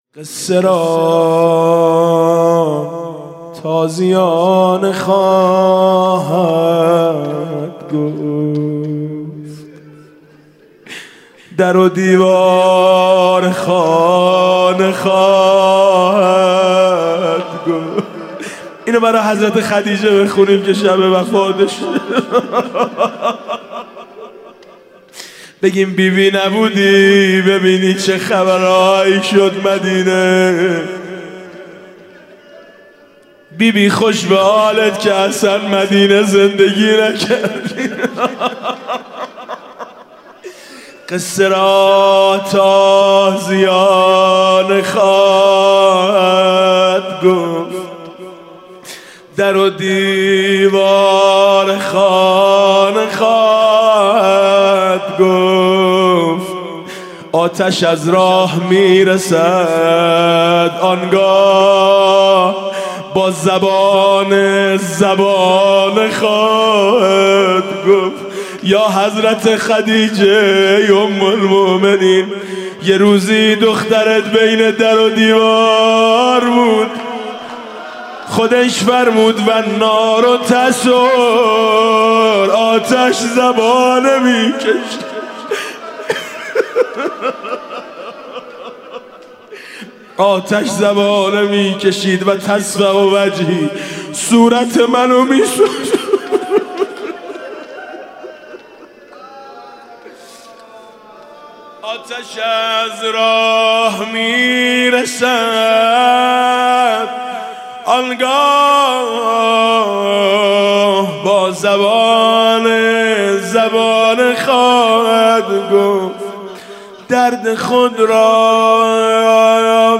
شب دهم رمضان 96 - هیئت شهدای گمنام - روضه - درد خود را به چاه و نخلستان
روضه